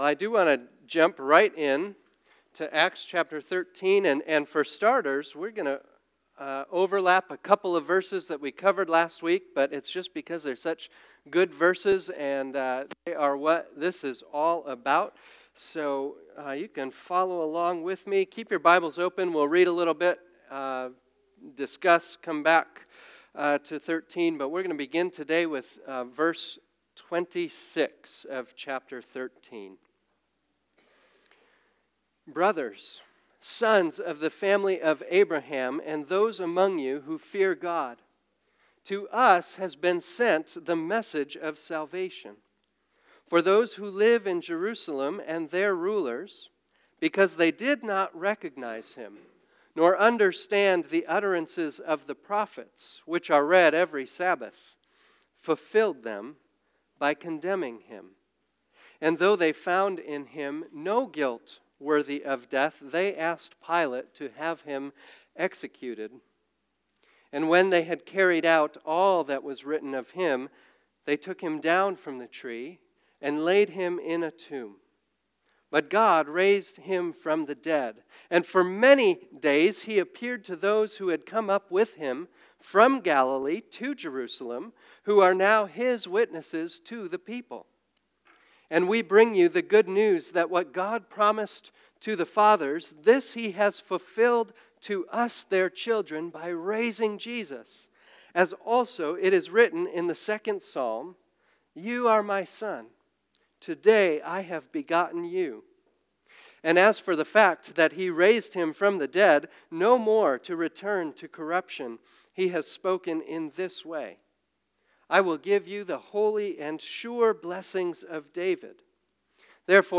Today we continue our sermon series, Acts: From the Upper Room to the Utter Most.